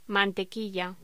Locución: Mantequilla
voz